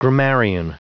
Prononciation du mot : grammarian
grammarian.wav